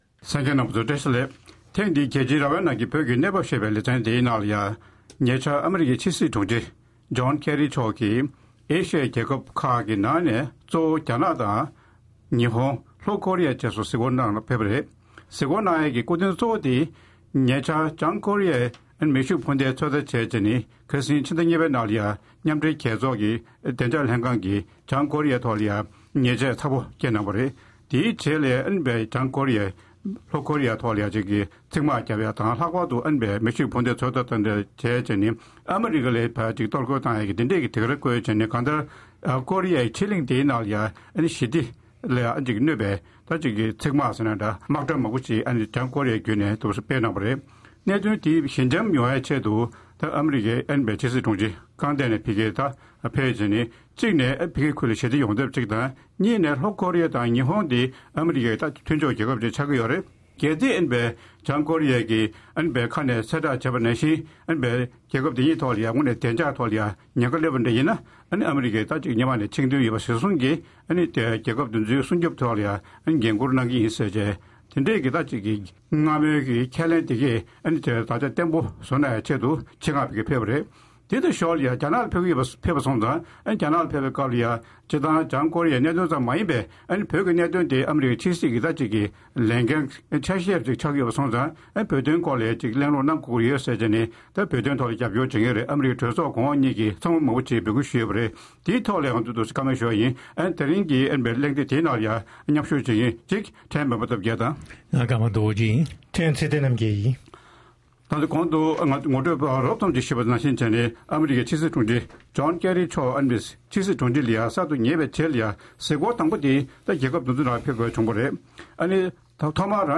དེའི་སྐོར་ང་ཚོའི་རྩོམ་སྒྲིག་འགན་འཛིན་དབར་དཔྱད་གླེང་ཞུས་པ་ཞིག་གསན་རོགས་གནང་།།